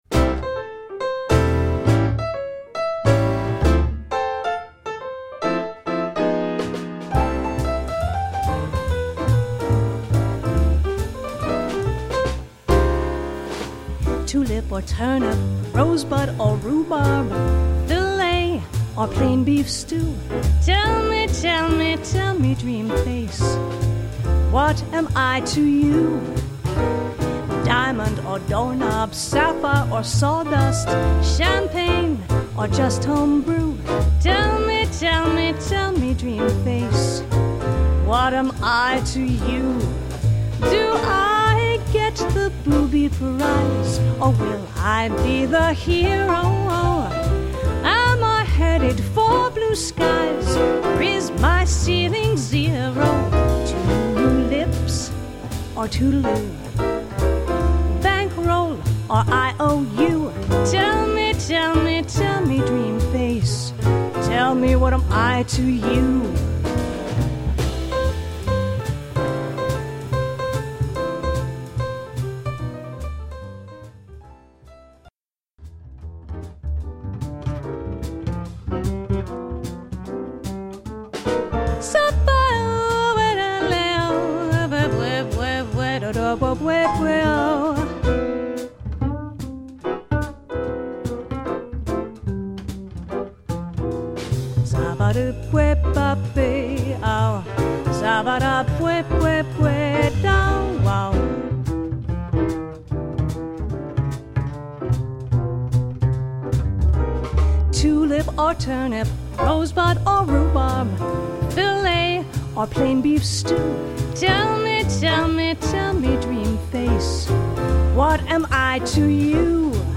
vocals
piano
double bass
drums